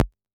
Index of /musicradar/retro-drum-machine-samples/Drums Hits/Raw
RDM_Raw_SR88-Perc.wav